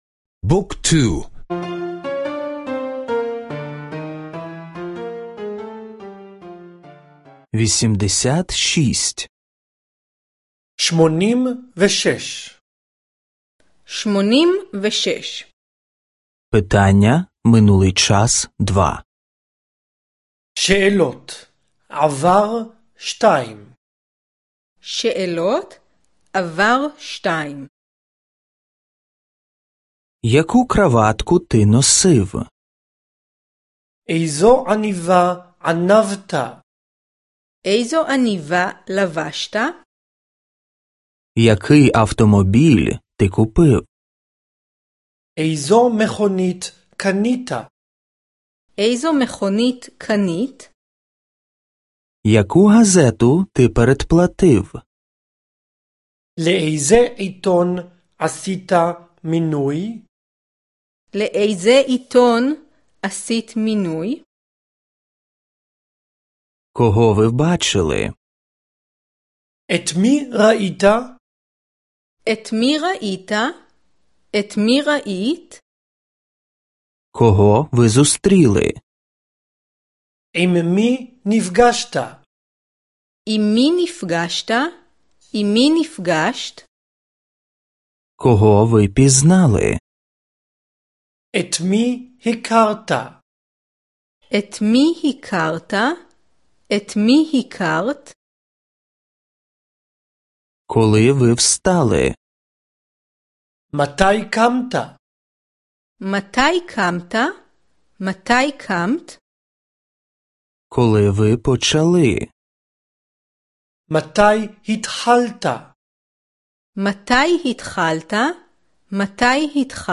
Аудіо уроки мови івриту — завантажити безкоштовно